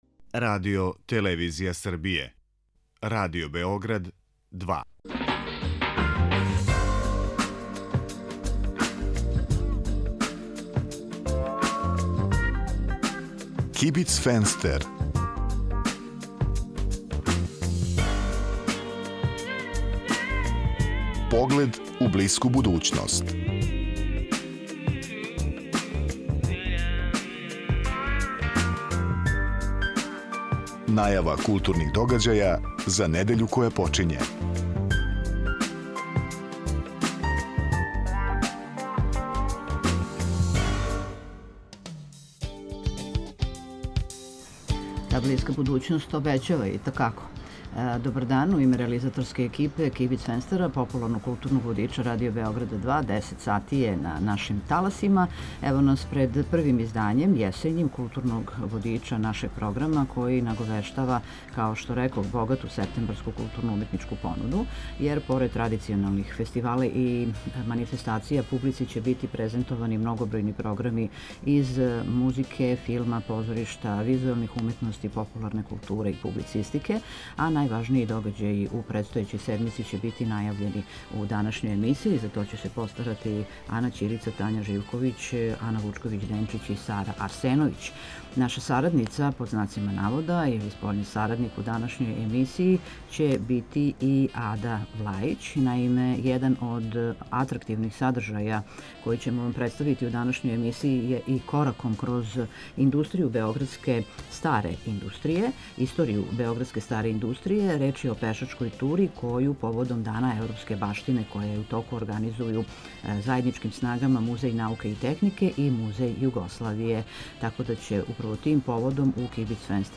Група аутора У некој врсти културног информатора за недељу која је почела, чућете аргументован избор и препоруку новинара и уредника РБ 2 из догађаја у култури који су у понуди у тој недељи. Свака емисија има и госта (госте), чији избор диктира актуелност – то је неко ко нешто ради у тој недељи или је везан за неки пројекат који је у току.